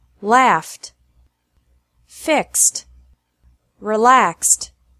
-ED pronounced like T
After regular verbs ending with an F / X sound